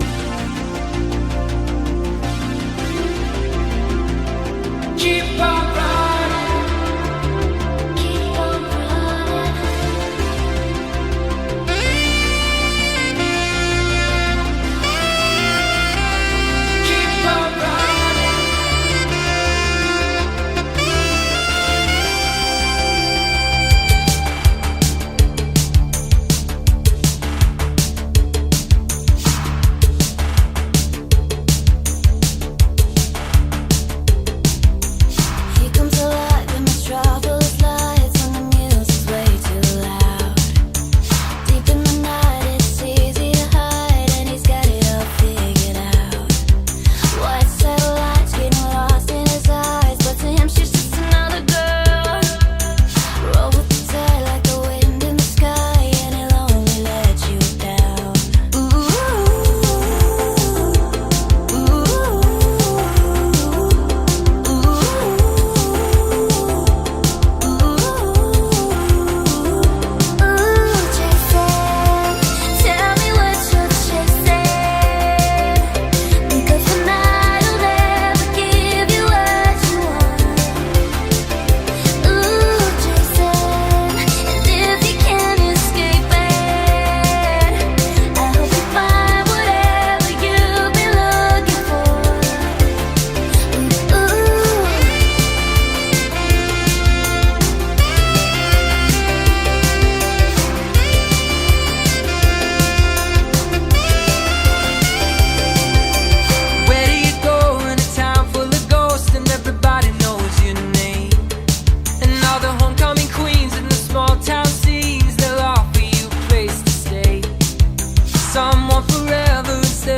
synthwave